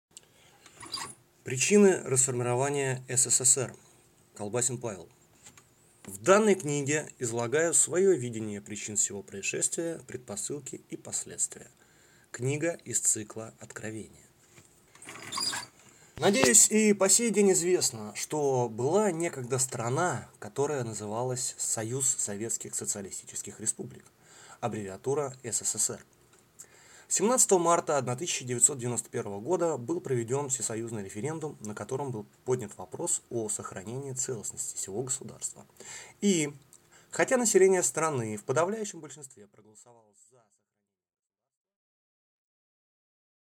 Аудиокнига Причины расформирования СССР | Библиотека аудиокниг